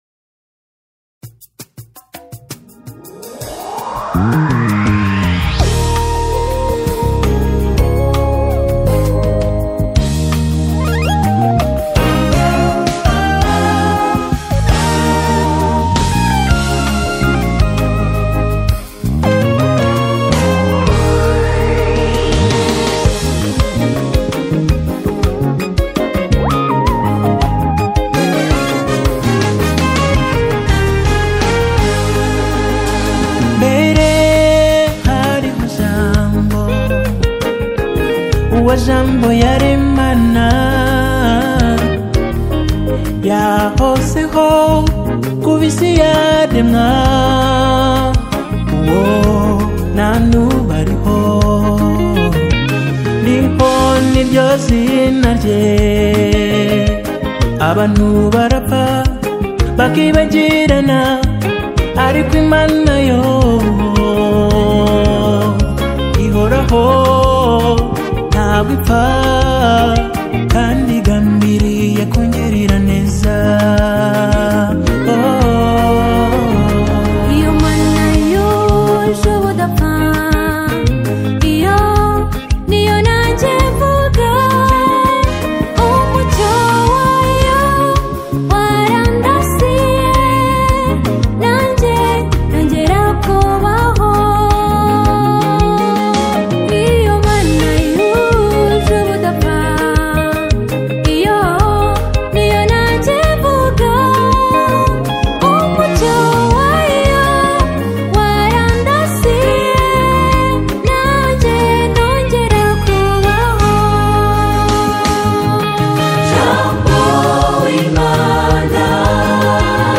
The rhythmically infectious and vocally massive single